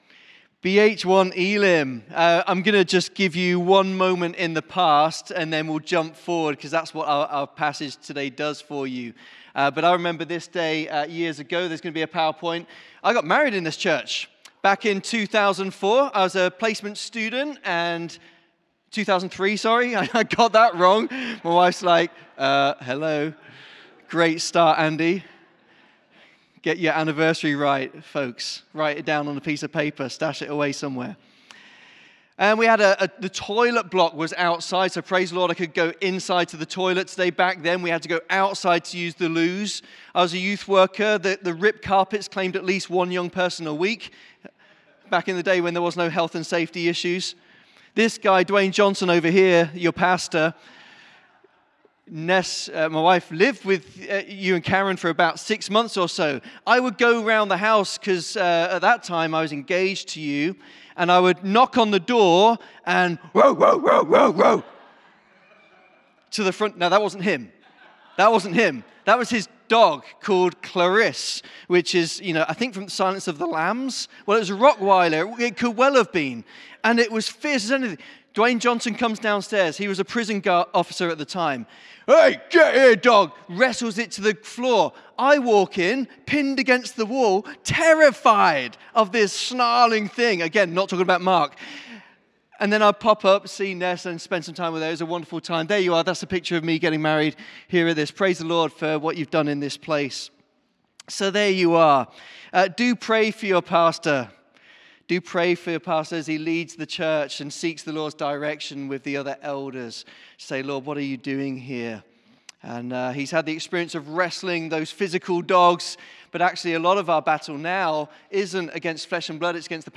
Sermon - Joel 2:21-28